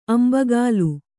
♪ ambagālu